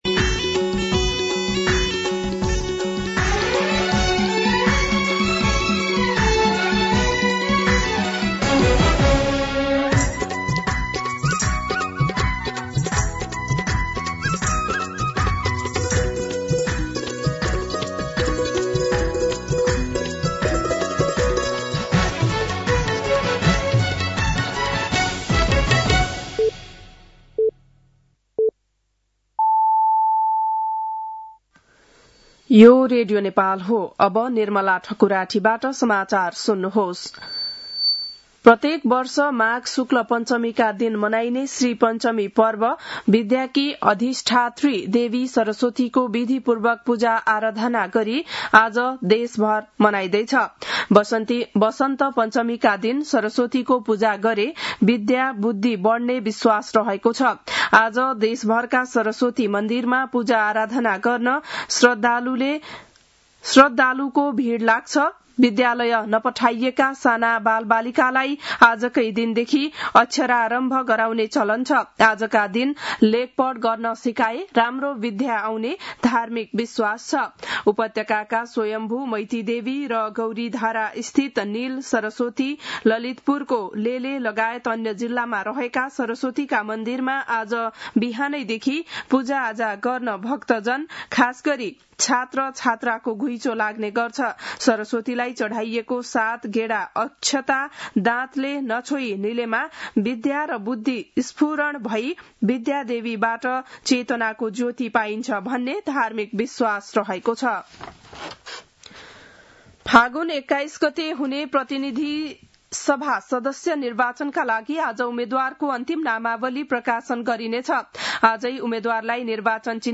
बिहान ११ बजेको नेपाली समाचार : ९ माघ , २०८२